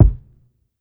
Beat Kick.wav